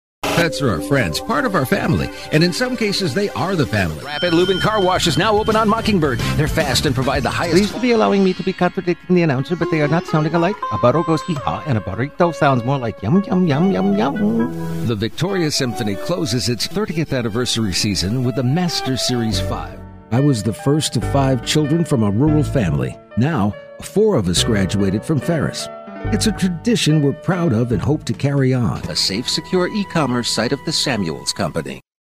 Mature, Genuine, Friendly